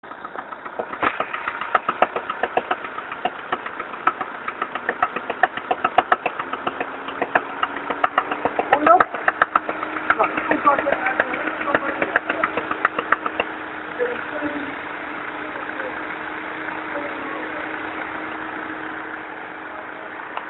Backfire geluid in inlaatspruitstuk
Wij hebben op het bedrijf een VW staan met een vreemd geluid er in .
Het klinkt alsof er een backfire plaatsvindt bij de eerste cilinder dus vooral bij belasten en bij het aanslaan soms als hij stationair draai met de airco aan zoals de audio fragment.